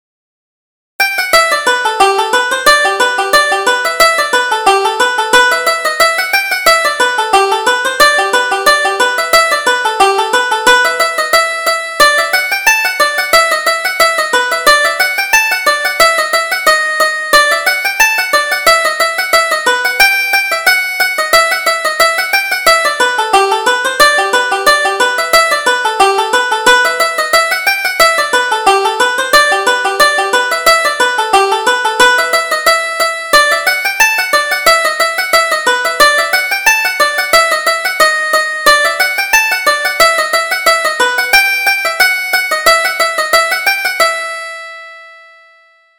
Reel: MacClean's Favorite